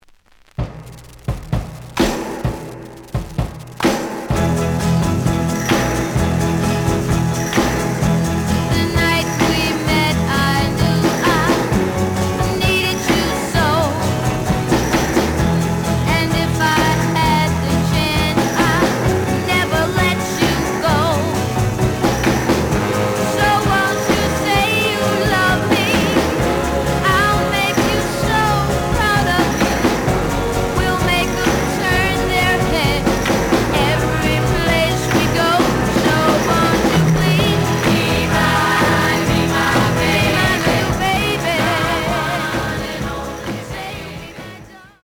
The audio sample is recorded from the actual item.
●Genre: Rock / Pop
Some noise on A side.